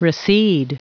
Vous êtes ici : Cours d'anglais > Outils | Audio/Vidéo > Lire un mot à haute voix > Lire le mot recede
Prononciation du mot : recede